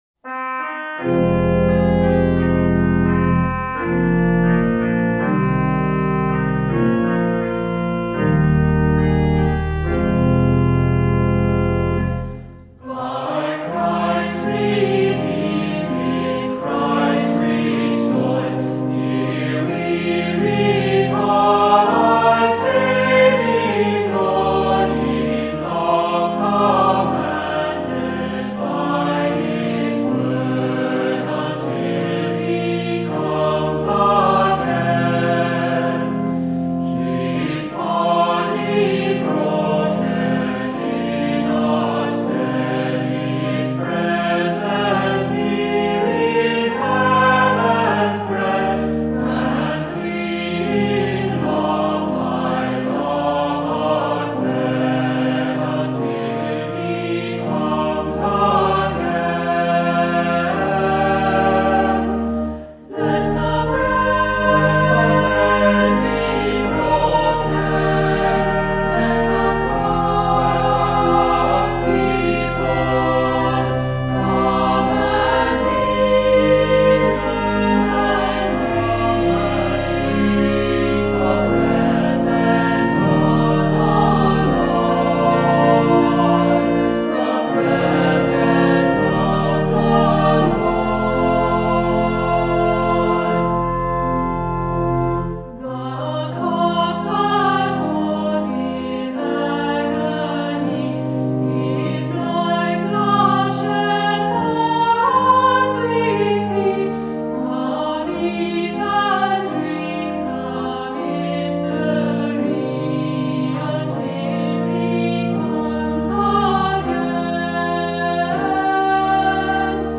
Voicing: SAB; Assembly